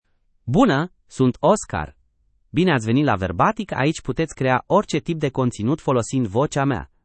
OscarMale Romanian AI voice
Oscar is a male AI voice for Romanian (Romania).
Voice sample
Listen to Oscar's male Romanian voice.
Male
Oscar delivers clear pronunciation with authentic Romania Romanian intonation, making your content sound professionally produced.